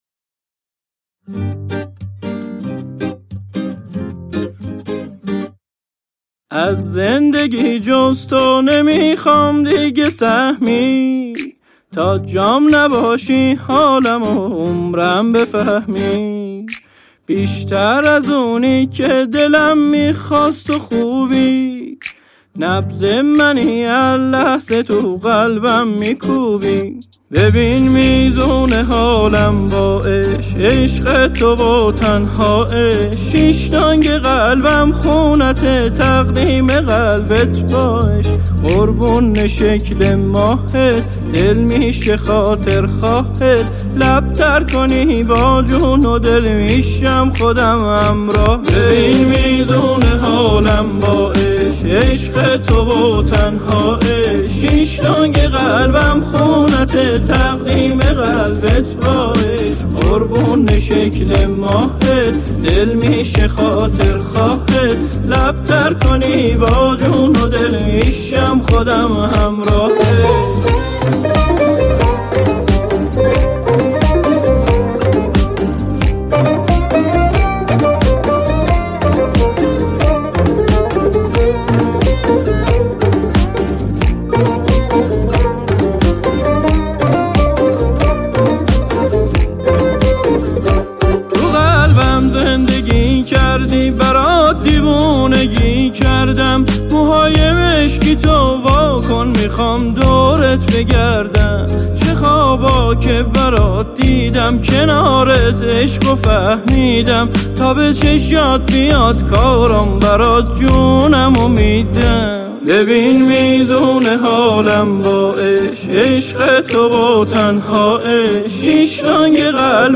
• آهنگ شاد